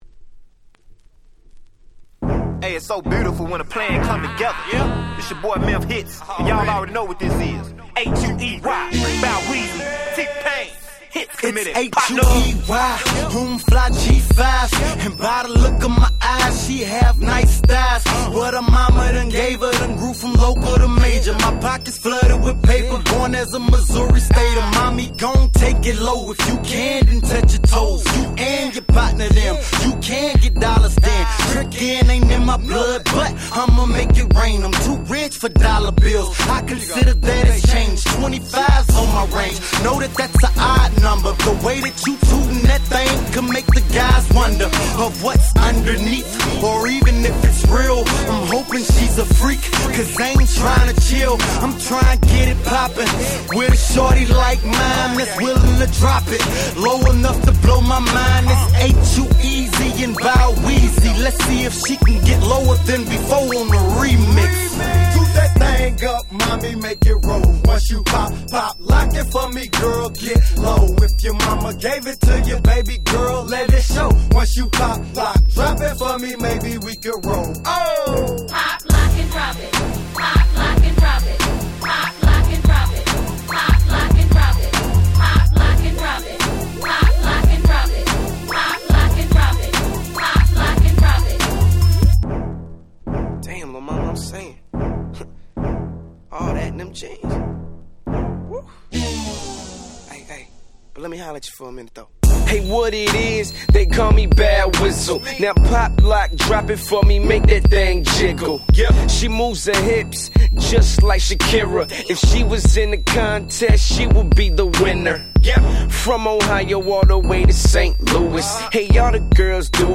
06' Super Hit Southern Hip Hop !!